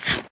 eat1.ogg